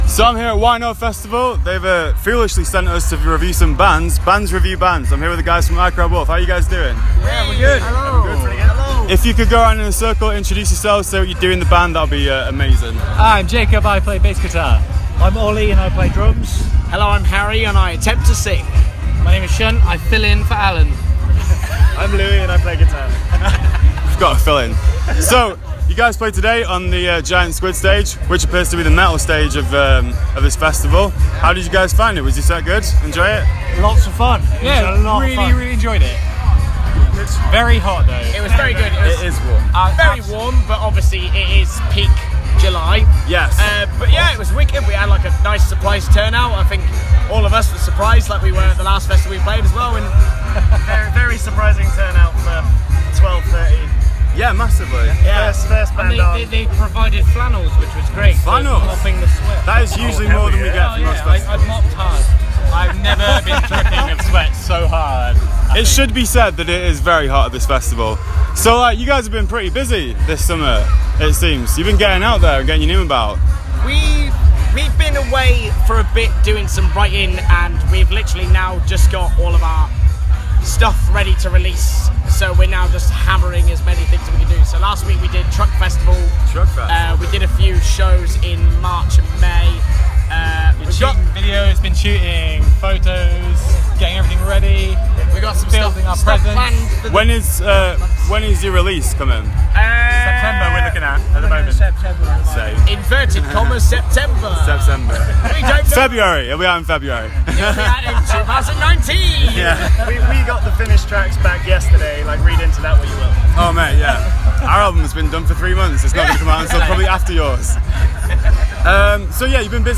I Cried Wolf – audio interview